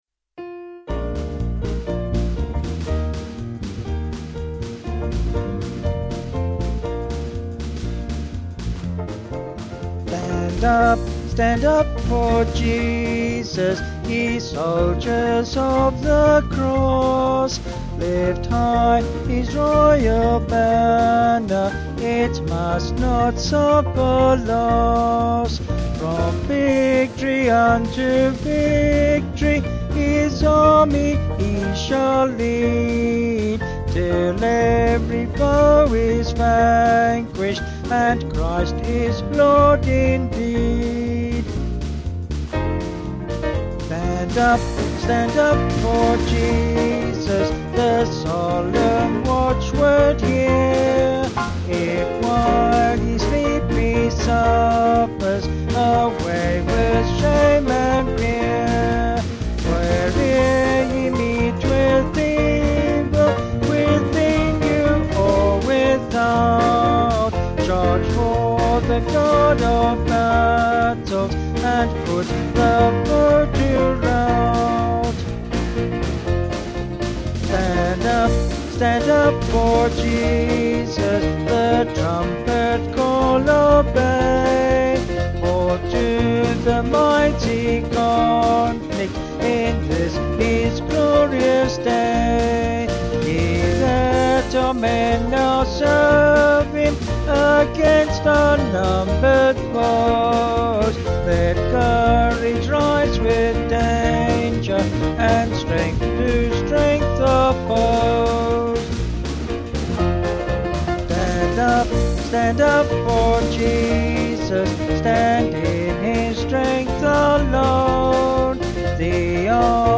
Vocals and Band   263.3kb Sung Lyrics